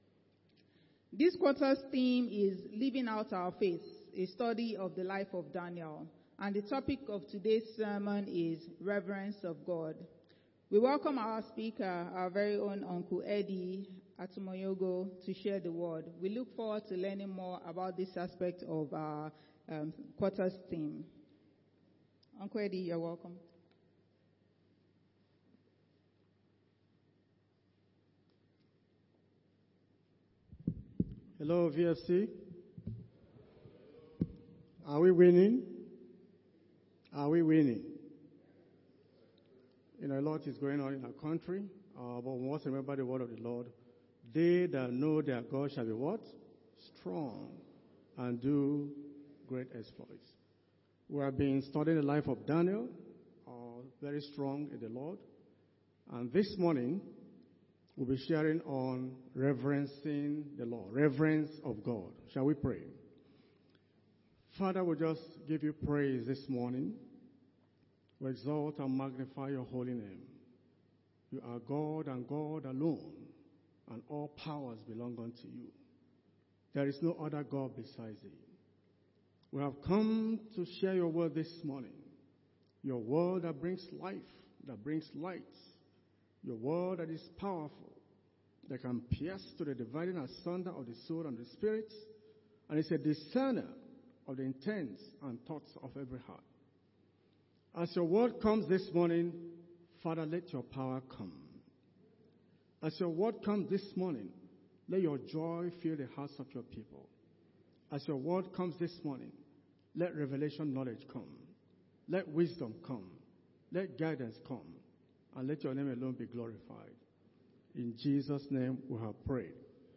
Sunday Service